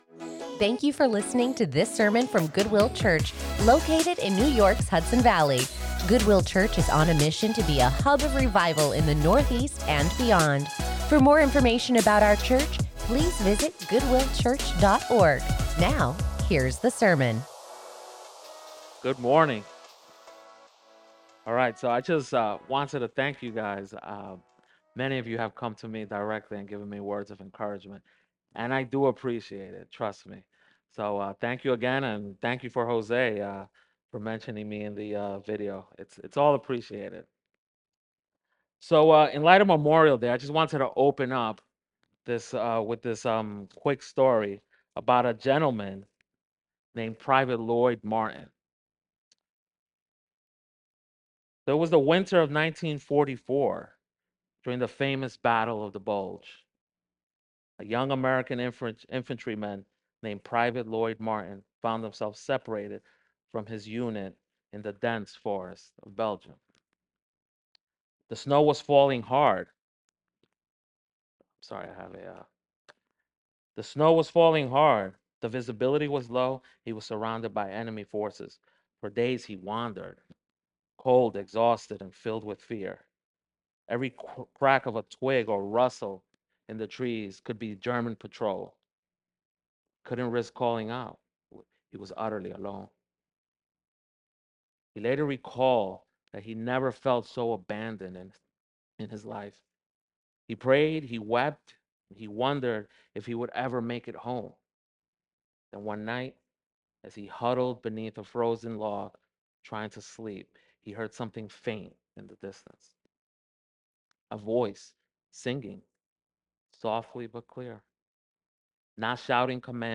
Join us in the study of God's Word as we continue our sermon series, Our Year With the Psalms